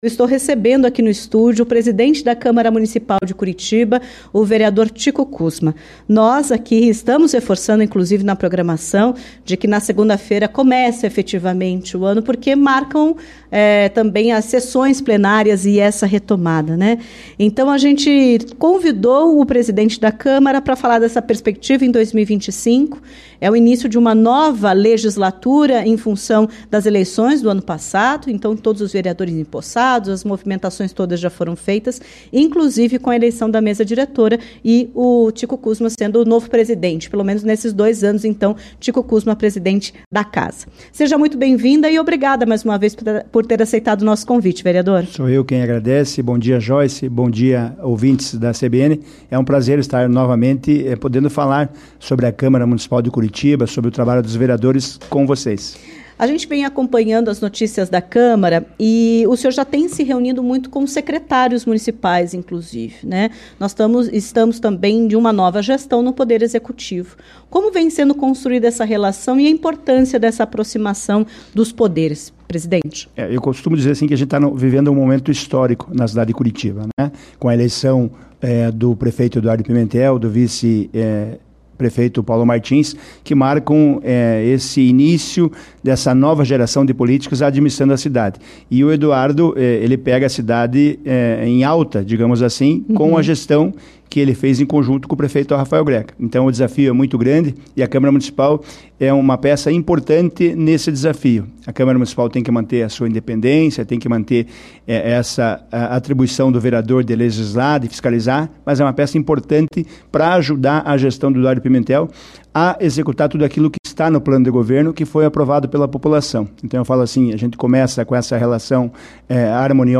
O presidente da Casa, Tico Kuzma (PSD), esteve nesta quinta-feira (30) na CBN Curitiba e comentou sobre os desafios deste ano no Legislativo. Ele ainda abordou sobre a renovação na Câmara, que chegou a 52% nas eleições de 2024, e como será o diálogo entre todos os vereadores, que possuem posicionamentos políticos divergentes.